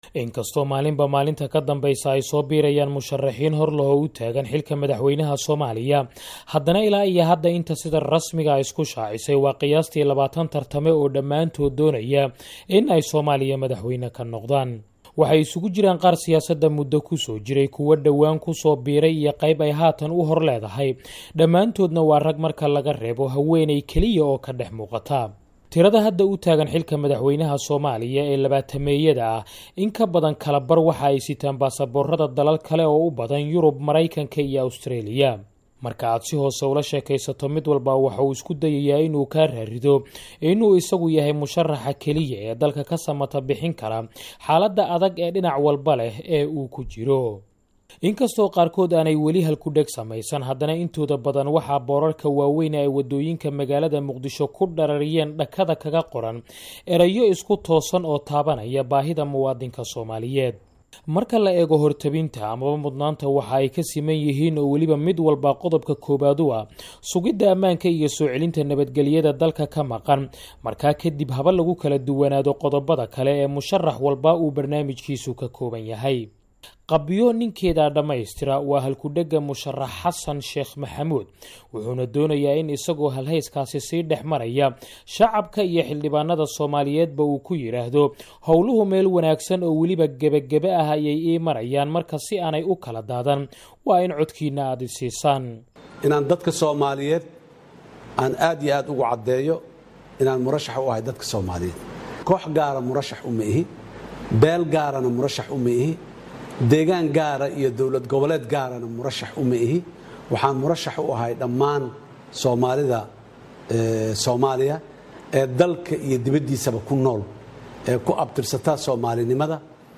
Warbixin: Musharraxiinta Ugu Cadcad Doorashada Soomaaliya